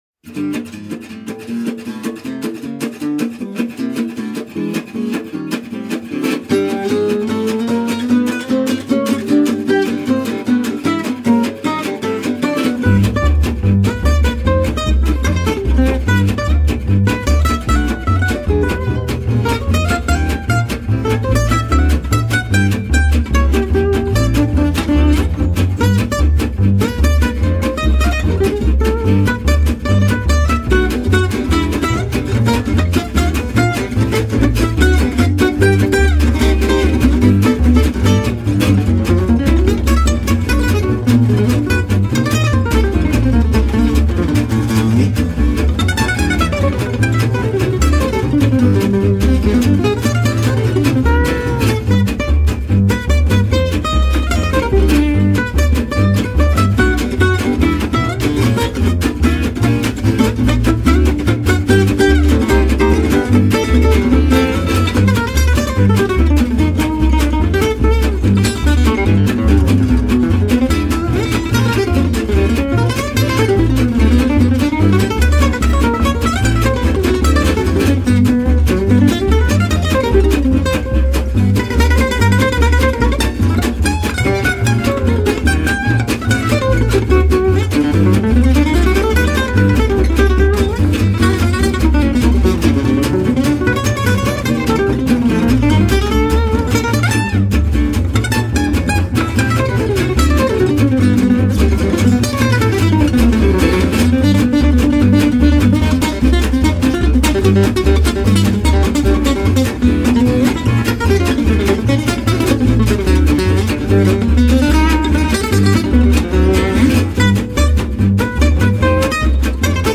solo guitar
rythm guitare
bass.